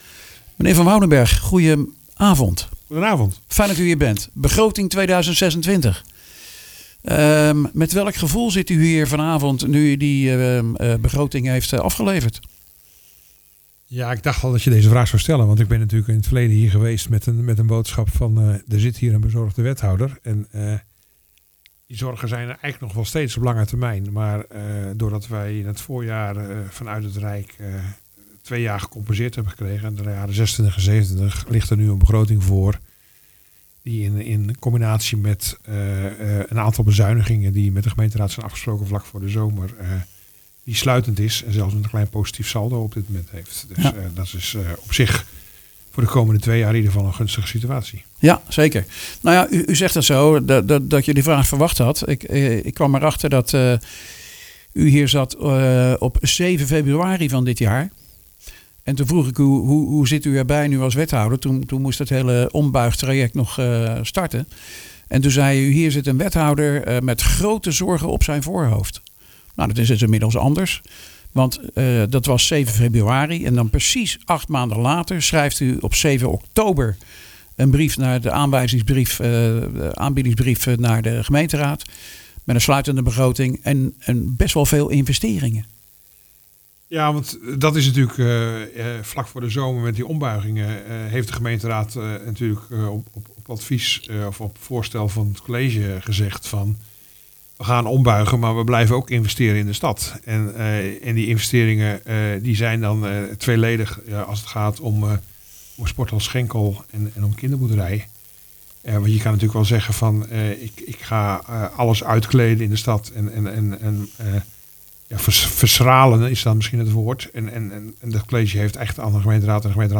in gesprek met wethouder�Financi�n Rik van Woudenberg.